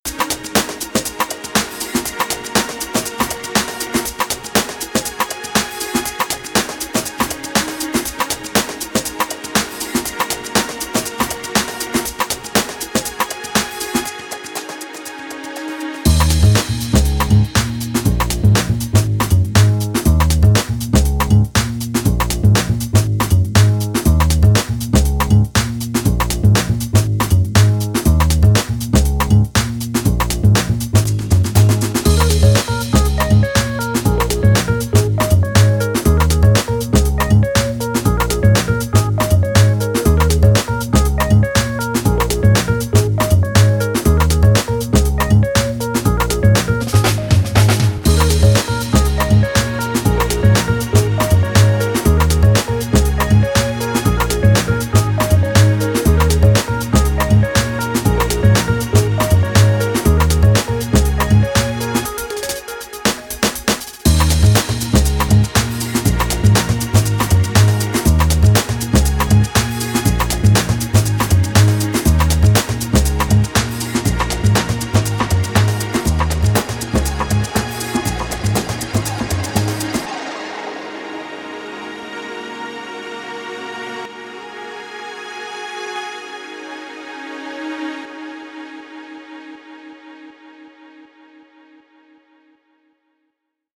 This is obviously quite a laid back track so intensity isn’t really called for but in other styles these builds could be used over larger sections of the track.
The finished mini arrangement
The whole track.